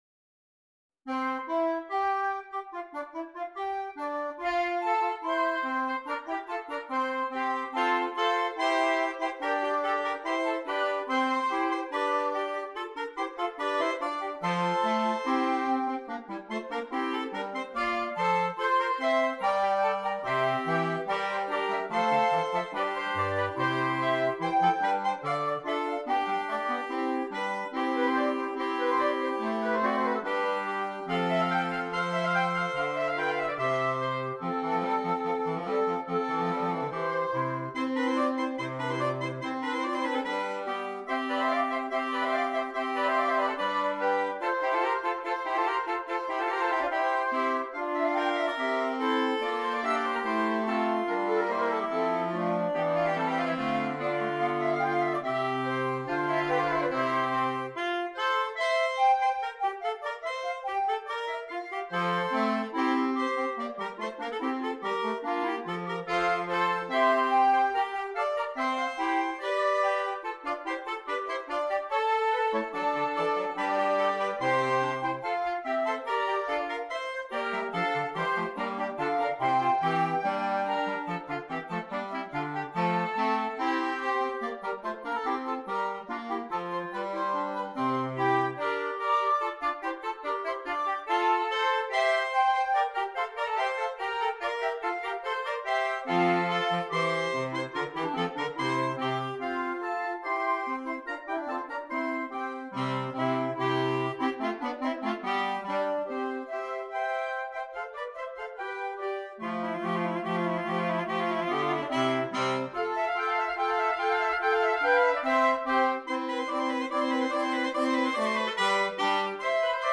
Gattung: Für gemischtes Holzbläserensemble
Besetzung: Ensemblemusik für Holzbläser variable Besetzung